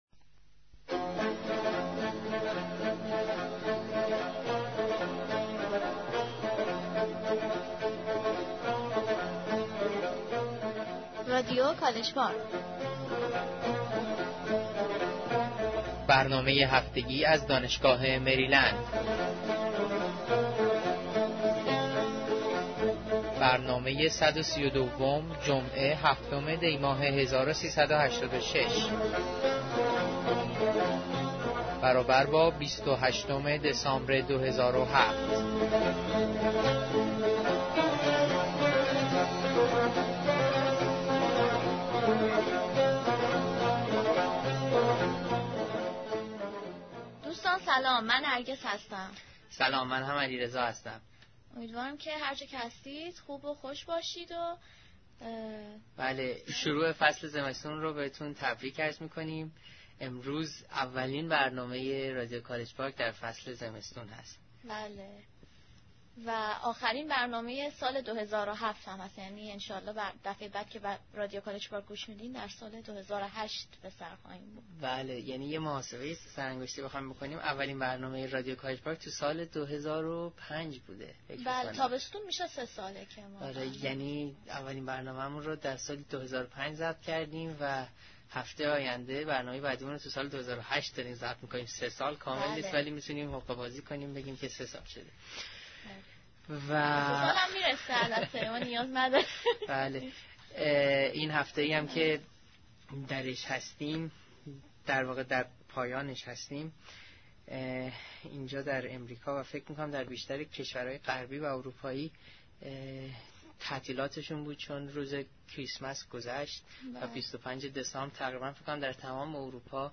Weekly News